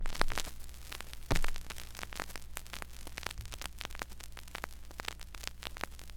StaticAndPop
pop record static vinyl sound effect free sound royalty free Sound Effects